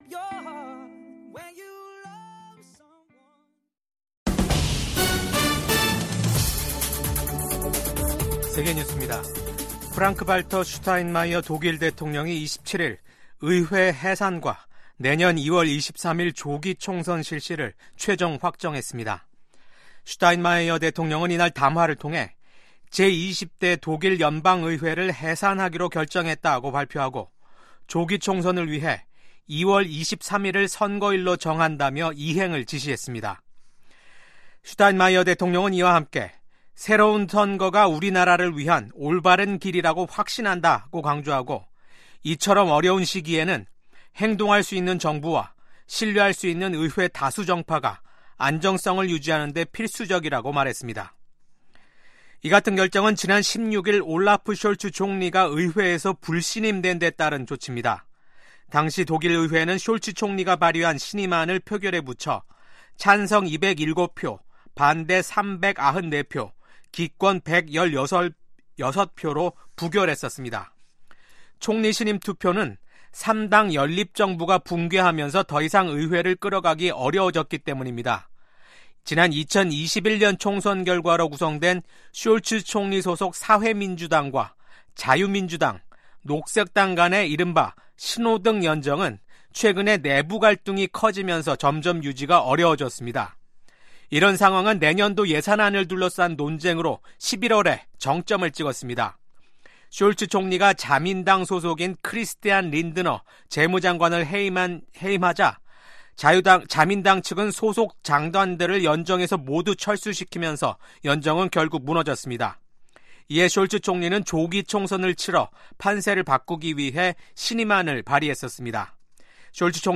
VOA 한국어 아침 뉴스 프로그램 '워싱턴 뉴스 광장'입니다. 한덕수 한국 대통령 권한대행 국무총리의 탄핵 소추안이 국회에서 가결됐습니다. 한국 국가정보원은 북한 군 1명이 러시아 쿠르스크 전장에서 생포됐다는 우크라이나 매체 보도에 대해 사실이라고 밝혔습니다. 미국 전문가들은 역내 안보환경을 고려할 때 미한일 협력의 필요성이 여전히 높다고 평가했습니다.